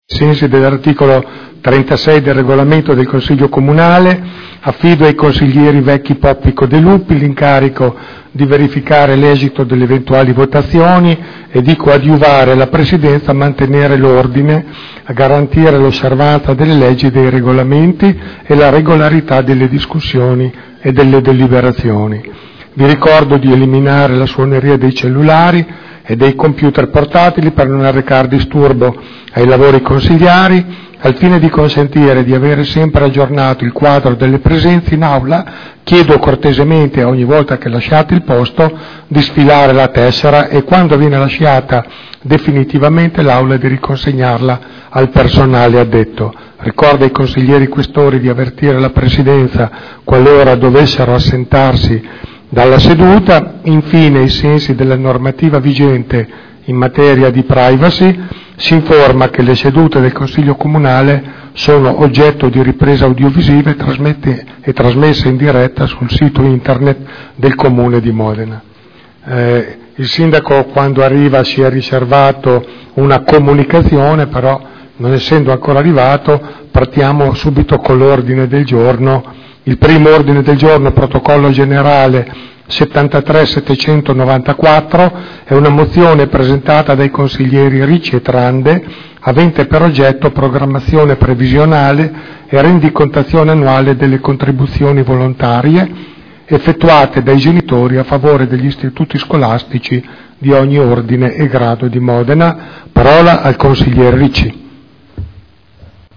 Seduta del 23/07/2012 Il Presidente Pellacani apre i lavori del Consiglio